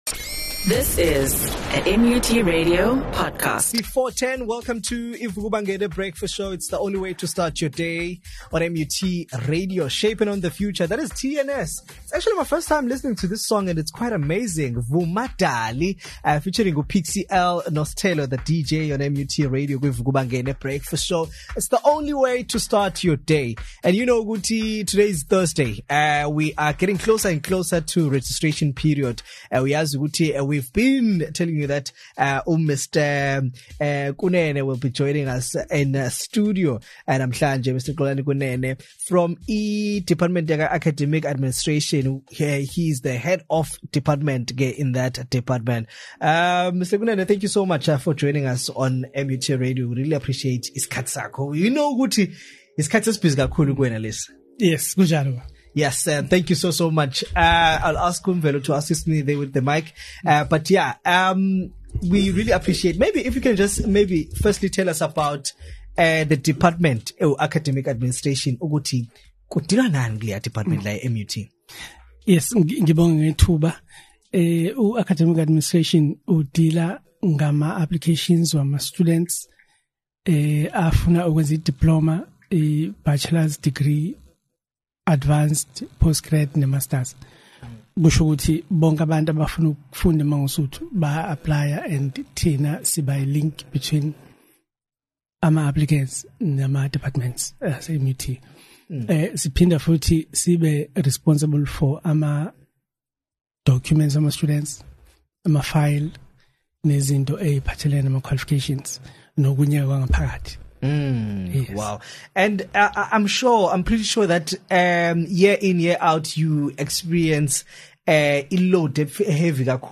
During this conversations he outlines the whole process of registration. He also reply to questions from the listeners and give advises to the incoming First year students.